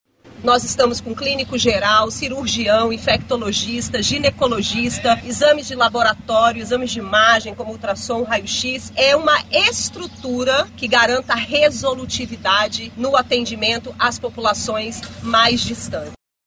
A expectativa é realizar mais de 3.000 atendimentos em 8 especialidades, como clínica geral Pediatria ginecologia e oftalmologia; destaca a secretária de Saúde, Nayara Maksoud.